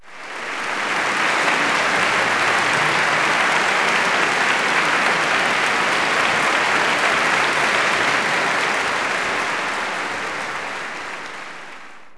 clap_034.wav